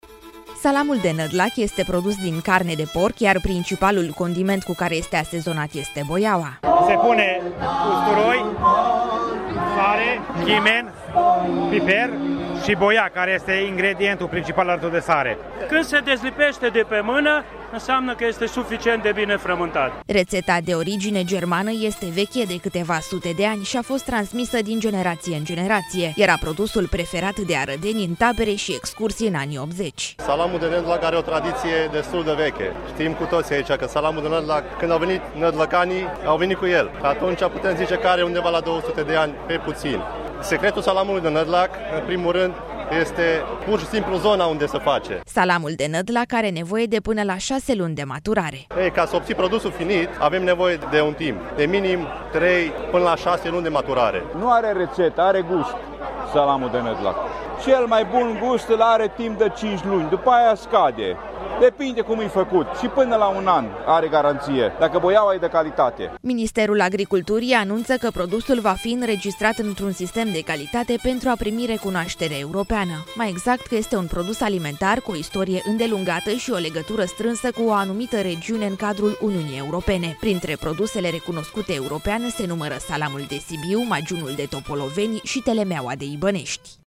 „Se pune usturoi, chimen, piper și boia – care este ingredientul principal alături de sare”, spune un bărbat.
„Când se dezlipește de pe mână înseamnă că este suficient de bine frământat”, spune alt bărbat.